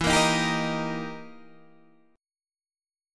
E Minor Major 7th Flat 5th